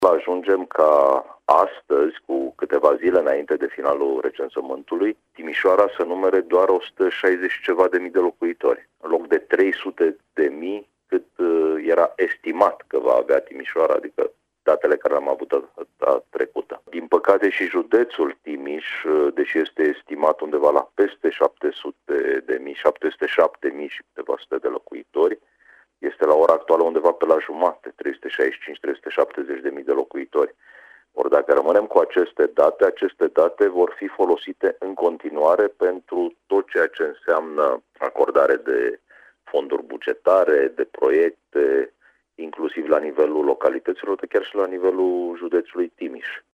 Subprefectul Ovidiu Drăgănescu a explicat, la Radio Timișoara, că acest lucru va avea implicații asupra finanțărilor ce vor putea fi obținute în viitor.